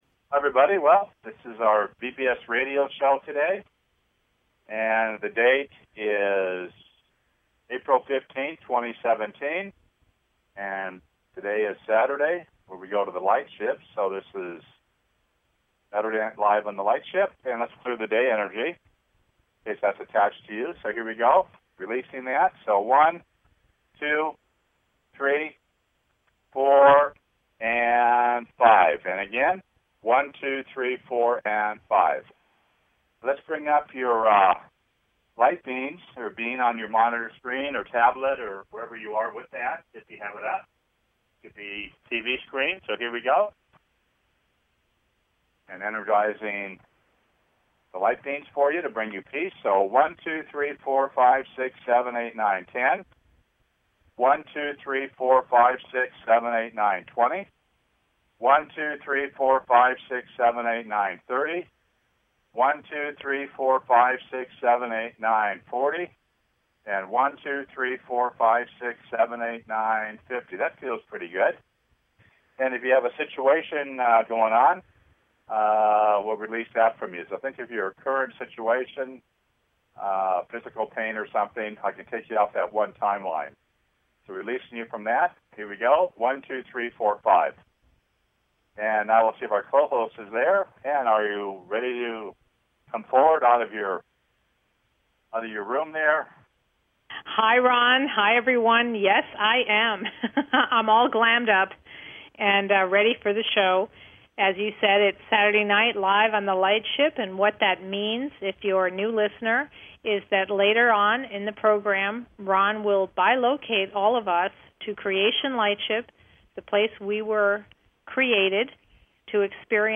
Tune into the Creation Lightship Healing BBS Radio Show to experience this Divine Lightship and its teachings.